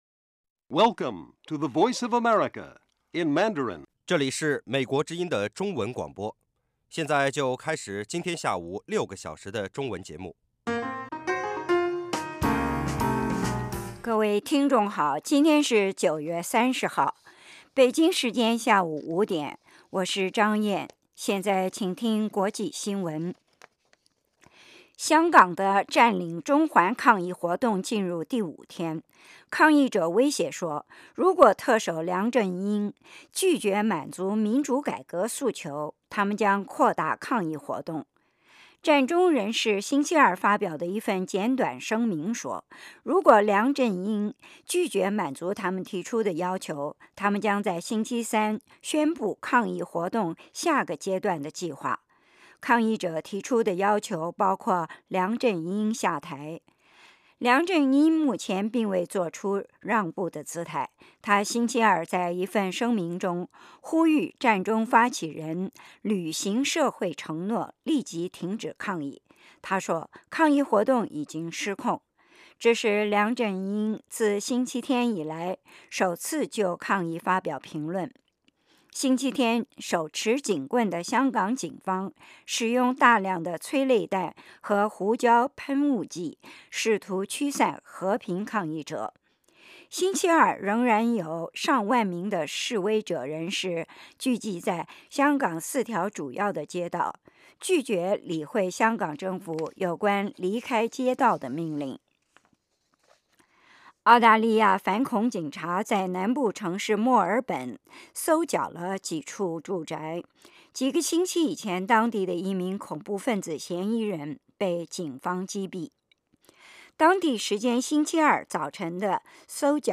晚5-6点广播节目